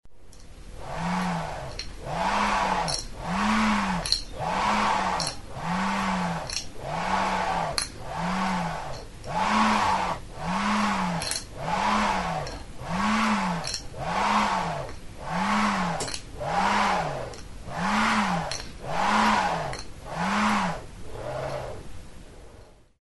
Music instrumentsFIRRINGILA
Aerophones -> Free-vibrating
Recorded with this music instrument.
Zurezko oholtxo bat da. Erdian bi zulotxo ditu eta horietatik sokatxo bat pasatzen da.